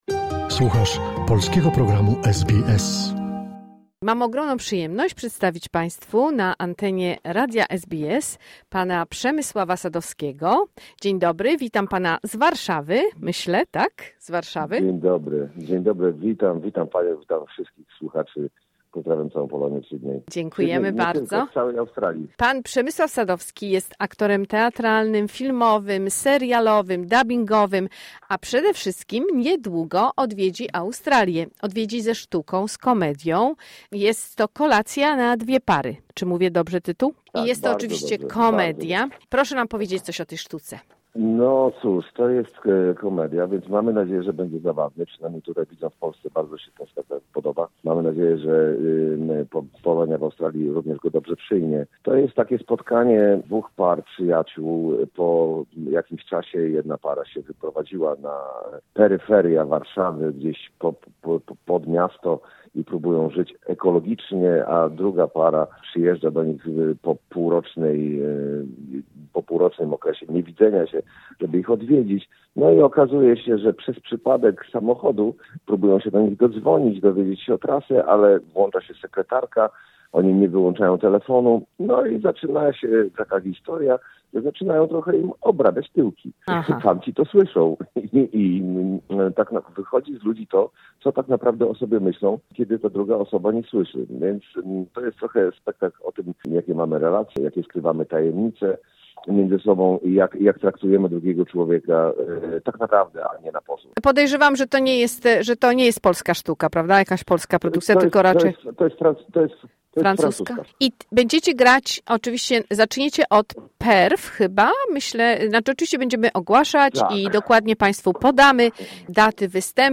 W ich wykonaniu zobaczymy sztukę komediową pt "Kolacja na Dwie Pary”. Posłuchajmy rozmowy z Przemysławem Sadowskim, który od najmłodszych lat marzył o pobycie w Australii...